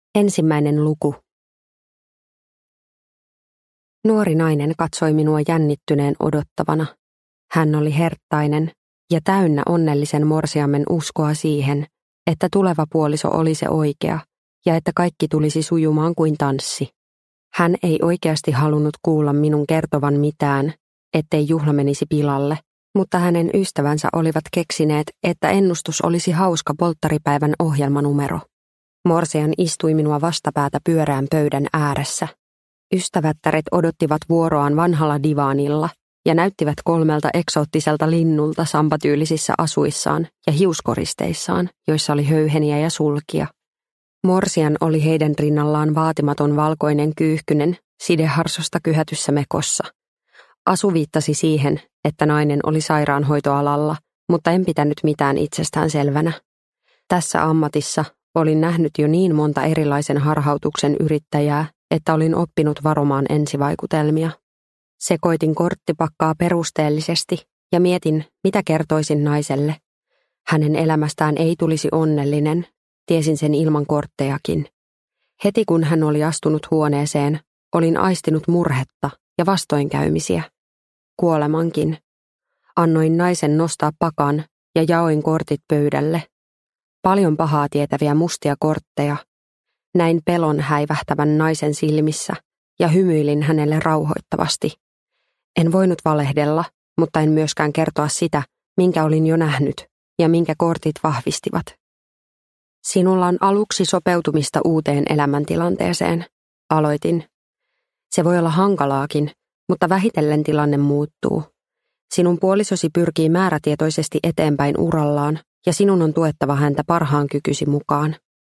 Franseska (ljudbok) av Tuija Lehtinen | Bokon